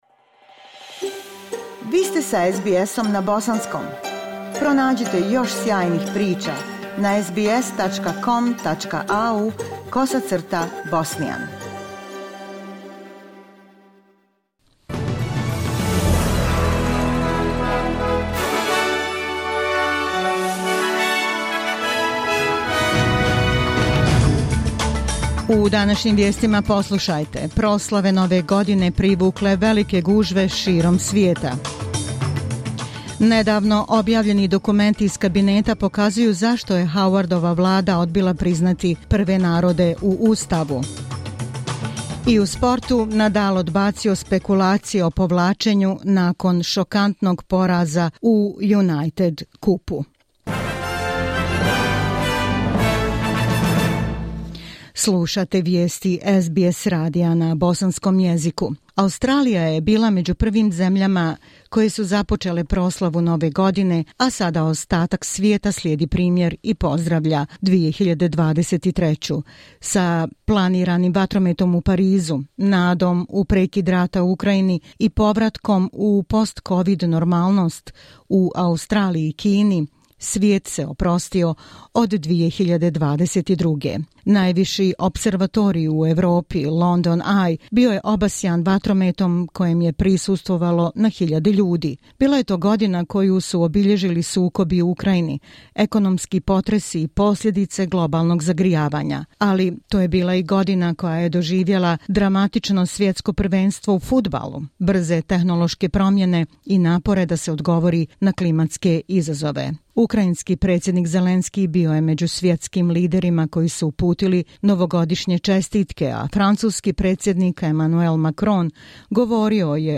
SBS radio news in the Bosnian language.